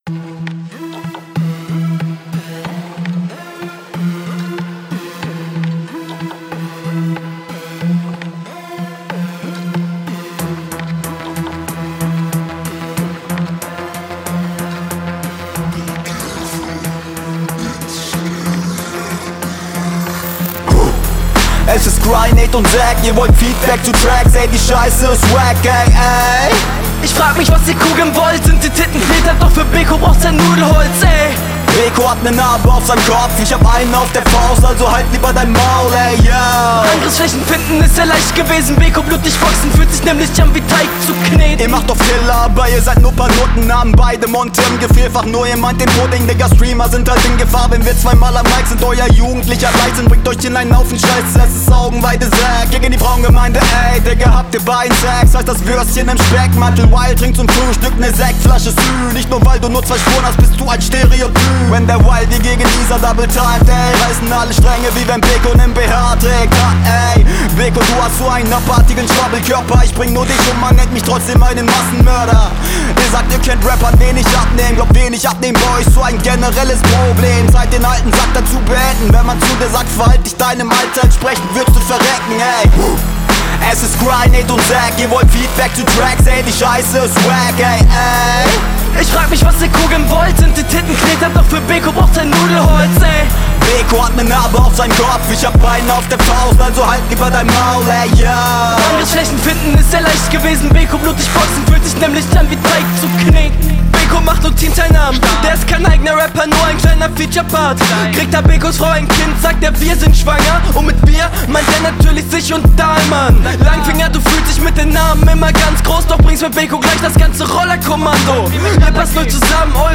Nicer Chorus. Guter Flow.